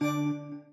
Appear_Window_Sound.mp3